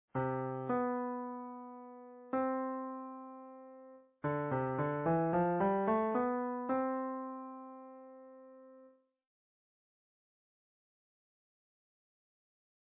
In the following idea, we've a clear and an undisguised destination of our melodic line, all heading to the tonic pitch 'C.'
:) Pretty clear that our melody is heading to our tonic pitch 'C' yes?